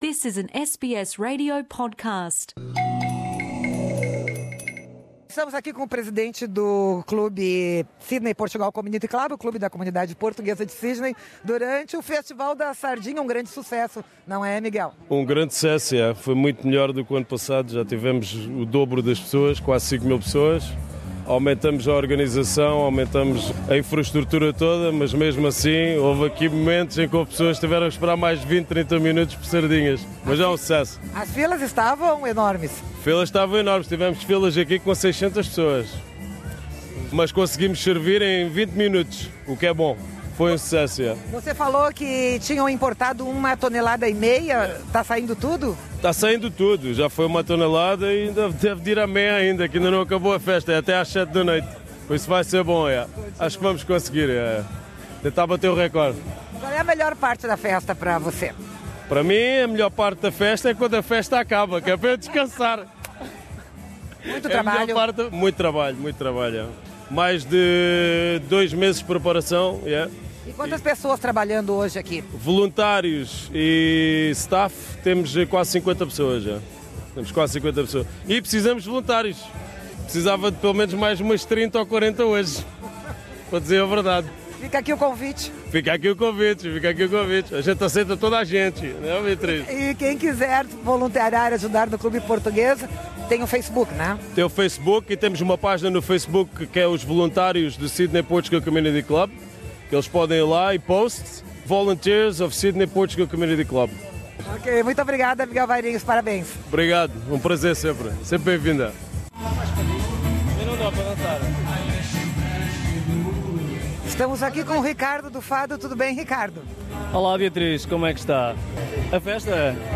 O Clube Português de Sydney, conhecido também como o clube do Fraser Park, importou uma tonelada e meia de sardinhas de Portugal para o festival do dia 4 de fevereiro - festival que bateu recorde de público no clube, com mais de 5000 pessoas. Ouça aqui o podcast das entrevistas.
Festa da Sardinha no Clube Português de Sydney - 4 de fevereiro de 2018 Source: by BW - SBS Portuguese